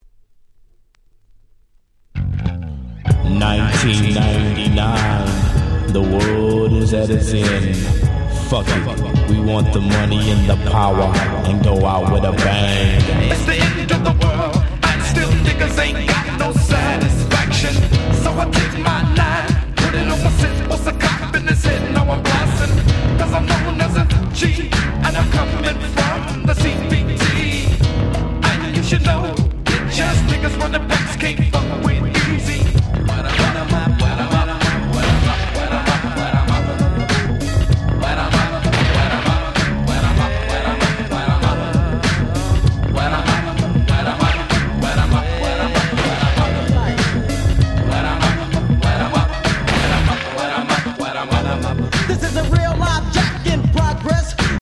93' Smash Hit West Coast Hip Hop !!